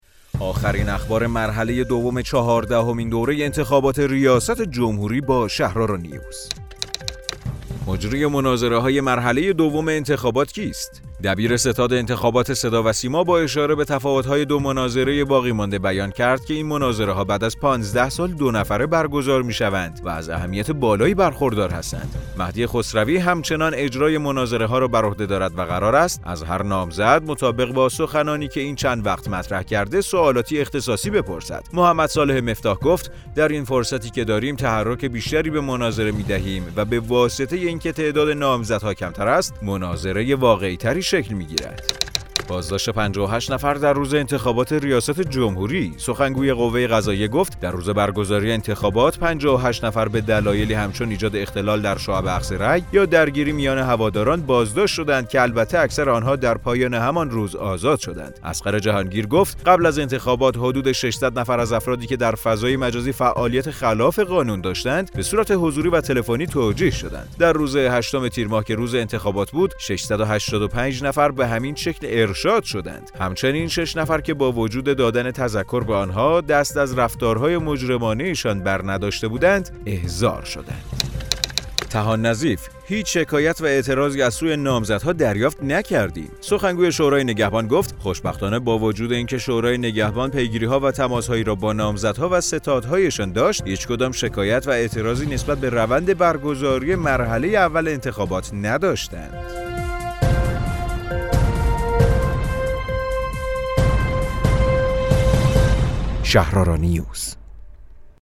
رادیو شهرآرا، پادکست خبری انتخابات ریاست جمهوری ۱۴۰۳ است.